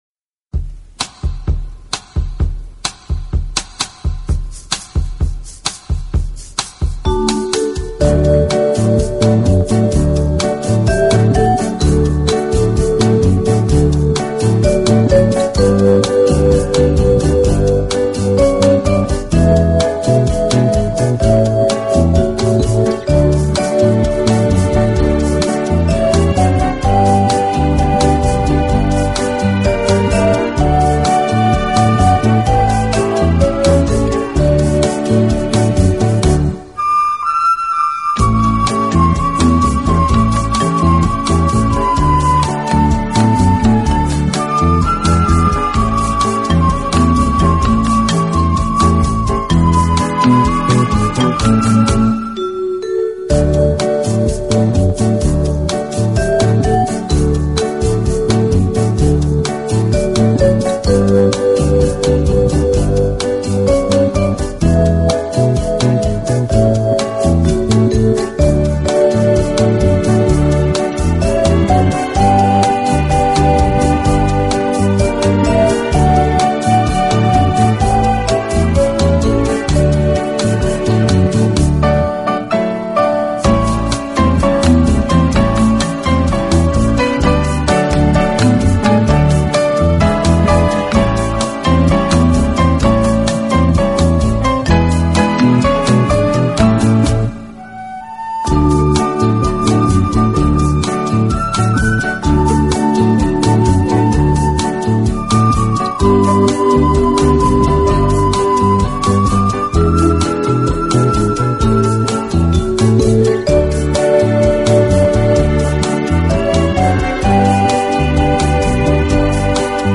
【轻音乐合辑】
所谓轻音乐（LIGHT MUSIC）是相对古典音乐而言的，即用通俗流行手法进行改编